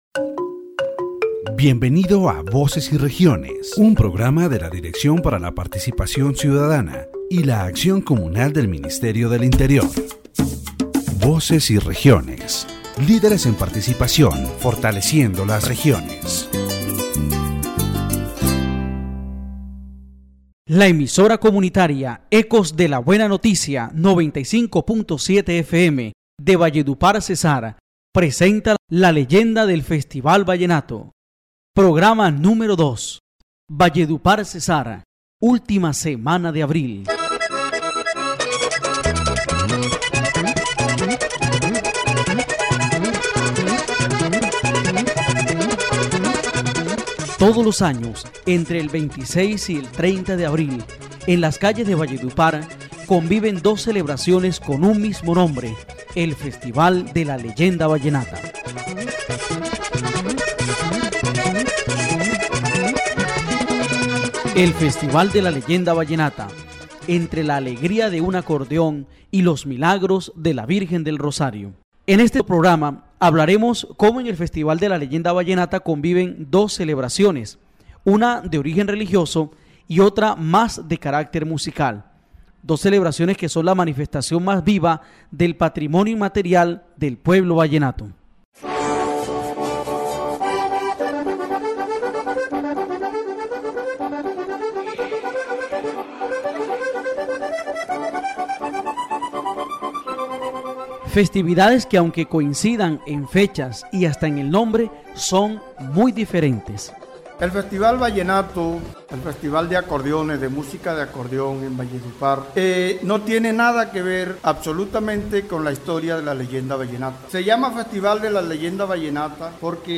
The radio program explores the duality of the celebrations that occur in Valledupar during the last week of April.